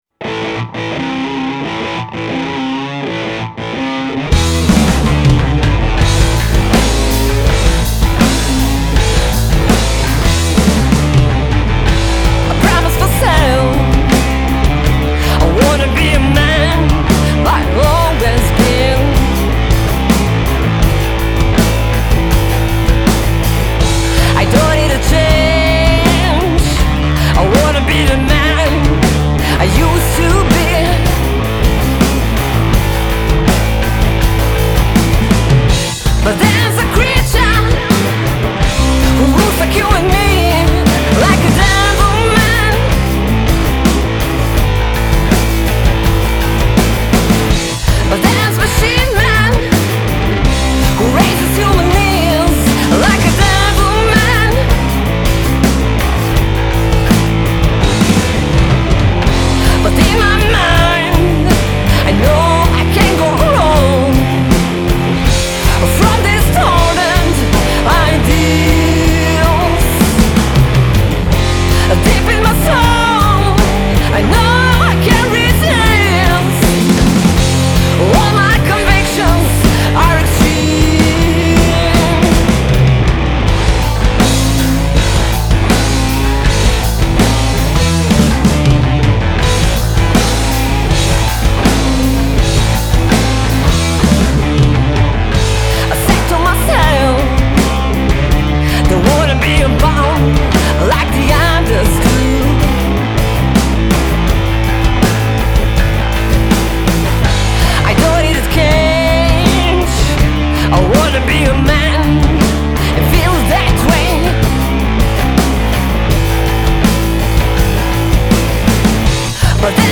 blues-rock trio